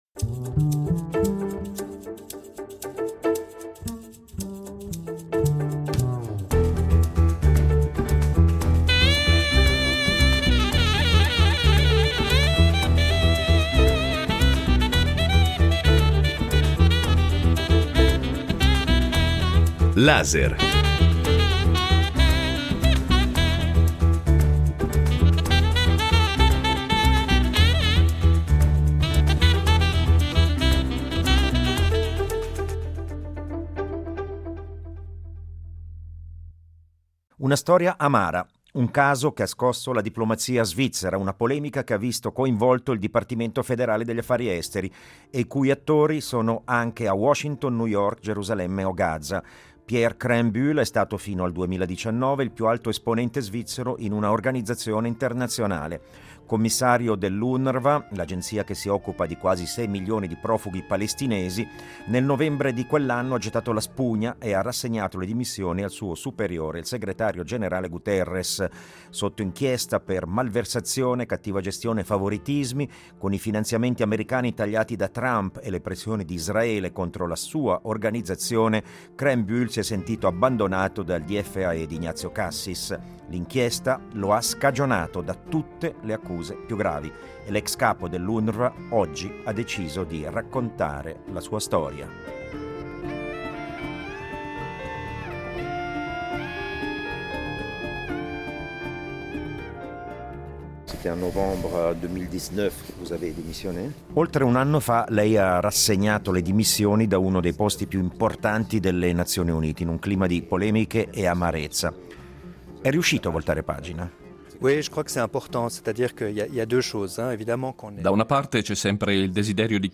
A "Laser" in un'intervista esclusiva Pierre Krähenbühl si confida, chiede giustizia, e racconta la sua verità in una vicenda dolorosa e dai contorni alquanto opachi.